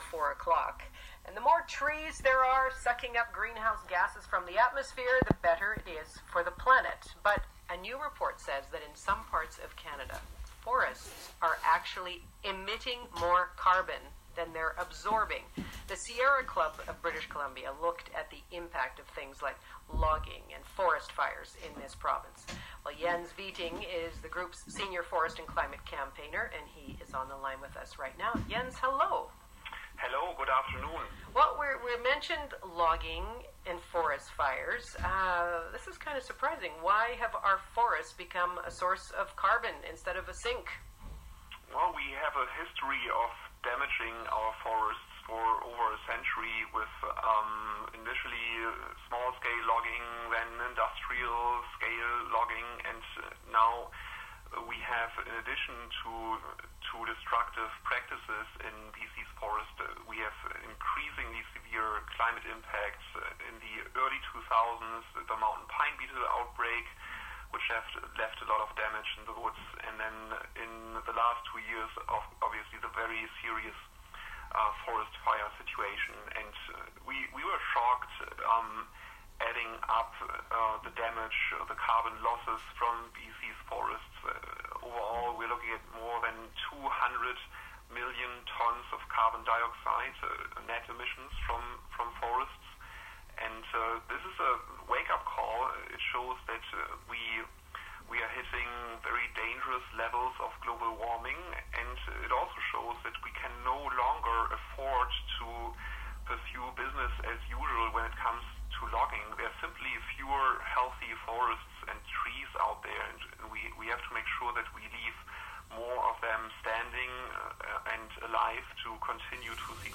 On the Coast Interview on BC forest emissions, CBC, January 28, 2019